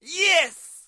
Звук yes, когда все получилось и ты рад